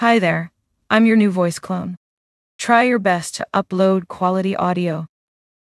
voice_sample.wav